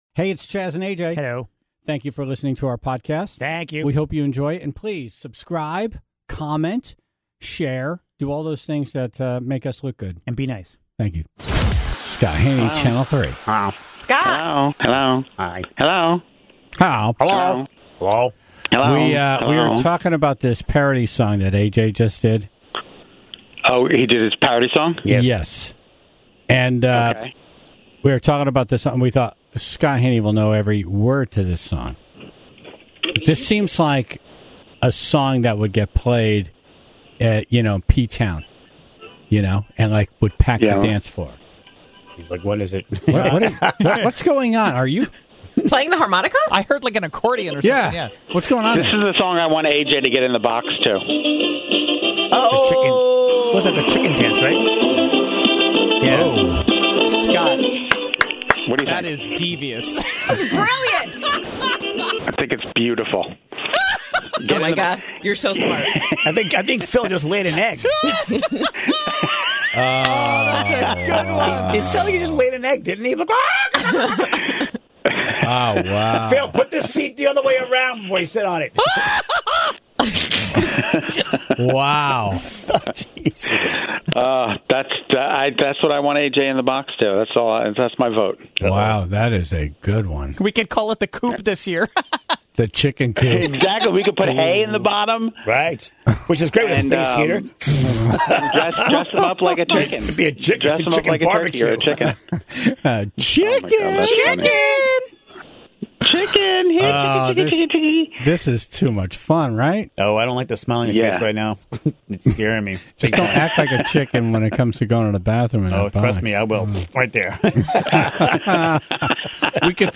(0:00) Dumb Ass News - The Flubble Montage! All the best moments of misspeaking on the air from this week of shows.